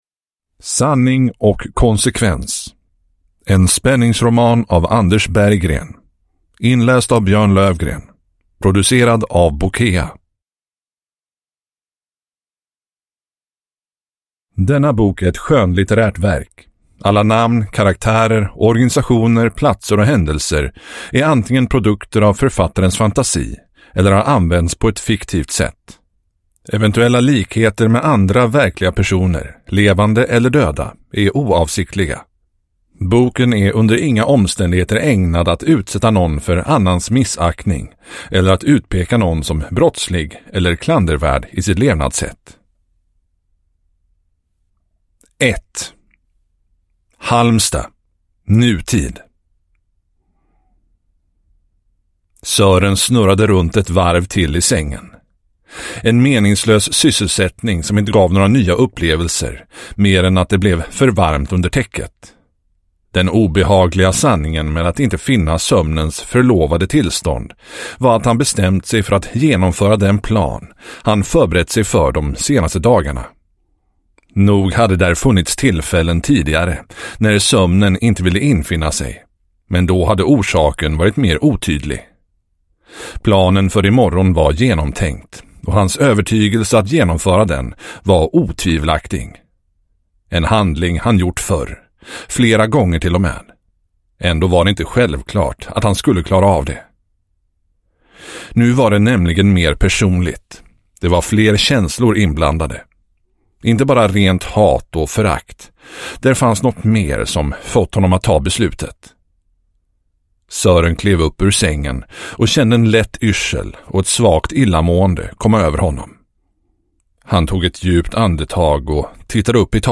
Sanning och konsekvens – Ljudbok